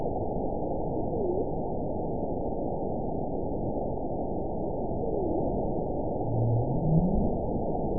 event 921897 date 12/21/24 time 08:54:25 GMT (4 months, 2 weeks ago) score 9.55 location TSS-AB02 detected by nrw target species NRW annotations +NRW Spectrogram: Frequency (kHz) vs. Time (s) audio not available .wav